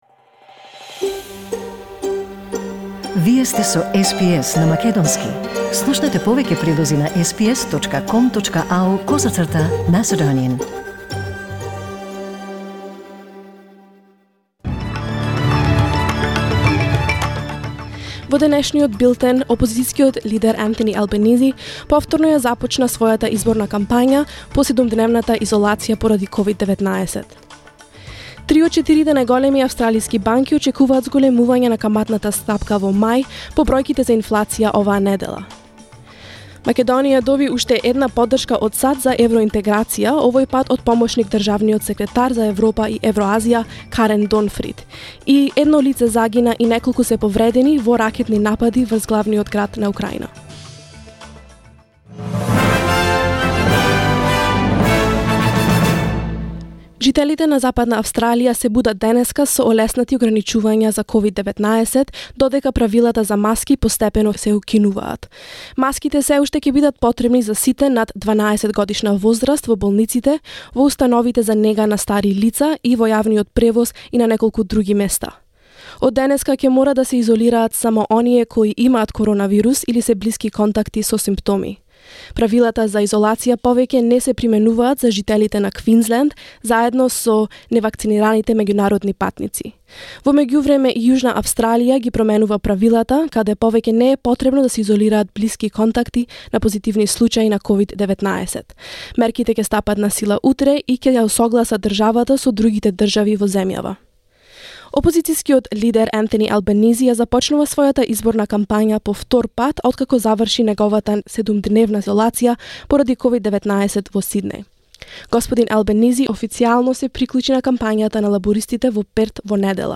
SBS News in Macedonian 29 April 2022